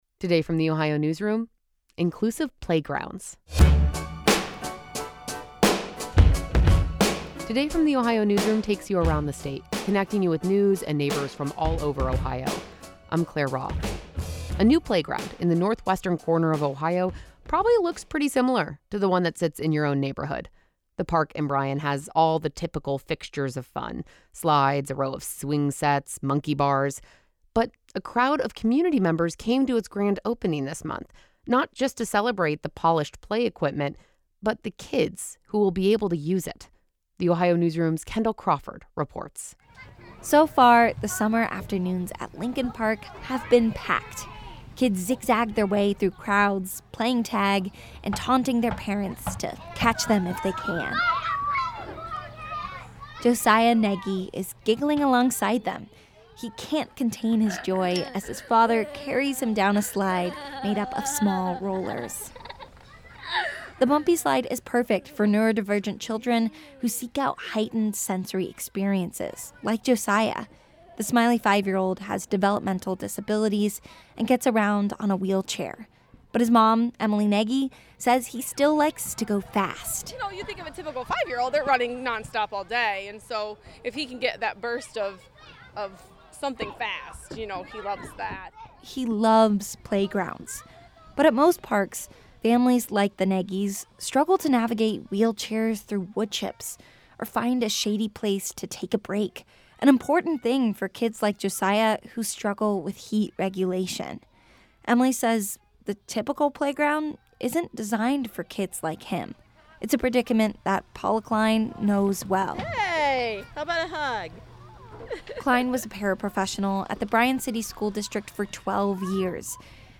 Squeals of delight echo through the park all afternoon, with the biggest bursts of joy coming from the park’s centerpiece, a tall green slide.
They take turns sliding down, each met with encouraging shouts by their parents.